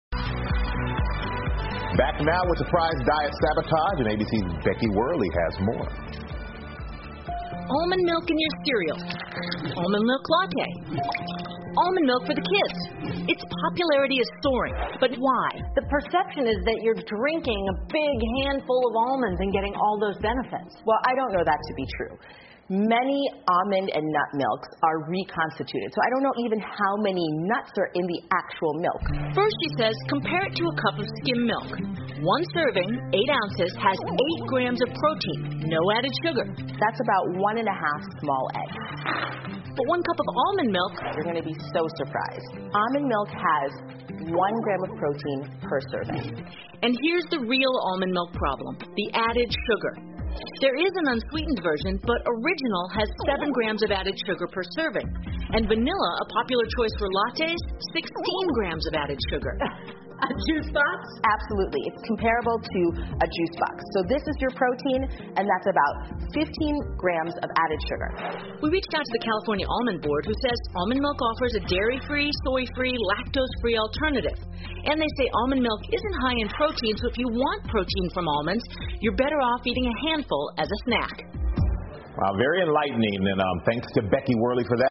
访谈录 超级食品：杏仁奶最流行 听力文件下载—在线英语听力室